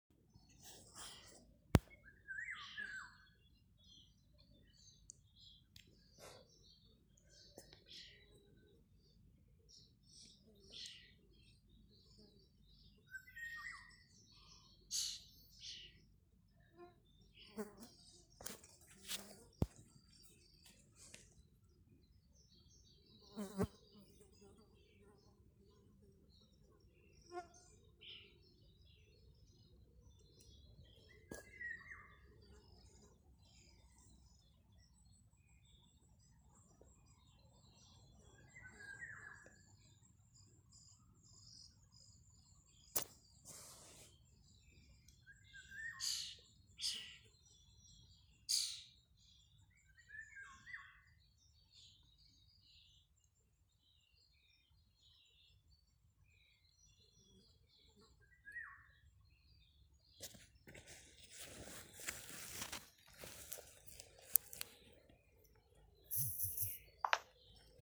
Golden Oriole, Oriolus oriolus
Ziņotāja saglabāts vietas nosaukumsValle
StatusSinging male in breeding season